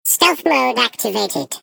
Sfx_tool_spypenguin_vo_enter_07.ogg